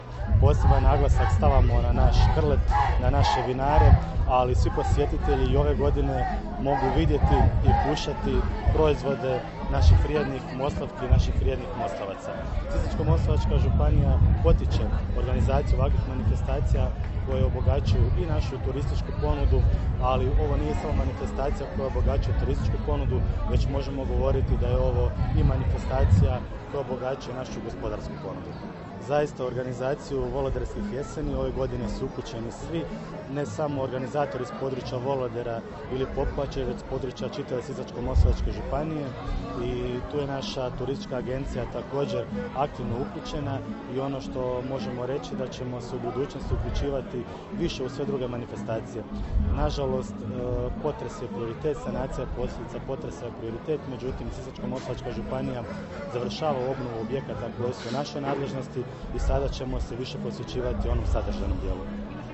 56. Volodersku jesen svečano je otvorio izaslanik predsjednika Hrvatskog sabora, župan Sisačko-moslavačke županije Ivan Celjak istaknuvši kako je ova manifestacija odavno prerasla manifestaciju samo od lokalnog značaja